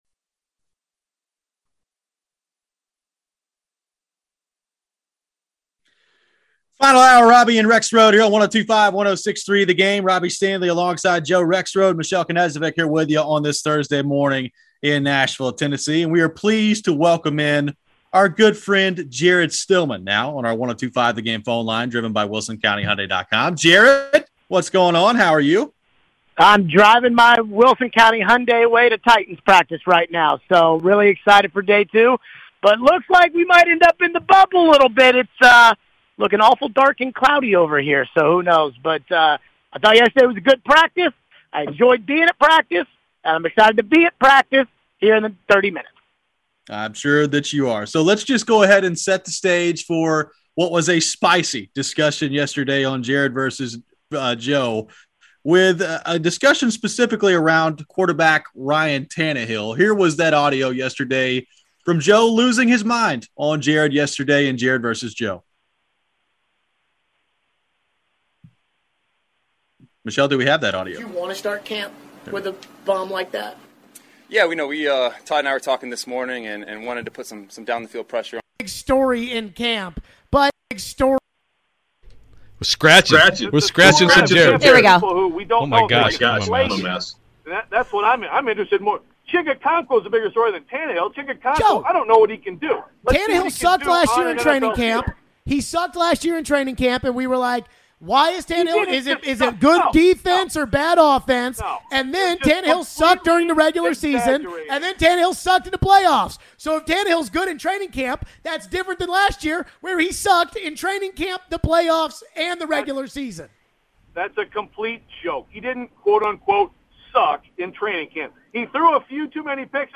A heated conversation about the biggest storylines in training camp.